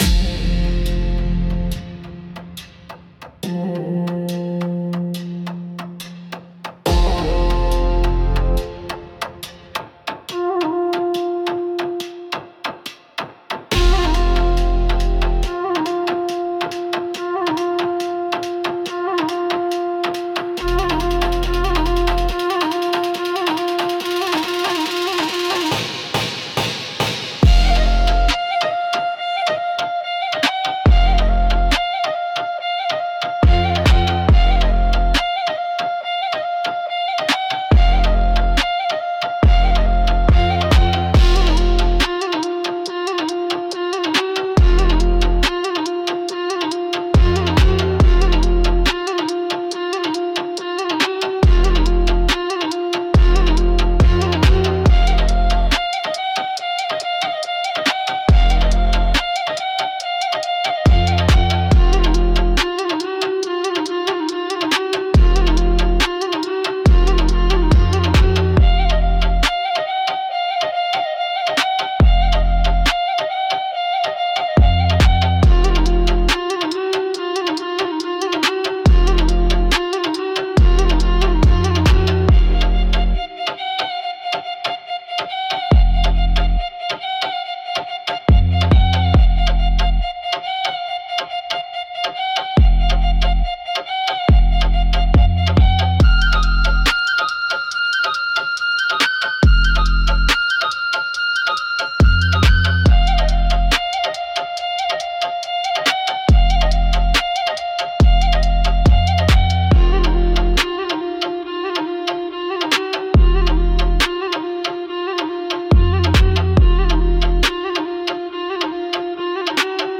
F Minor – 70 BPM
Drill
Hip-hop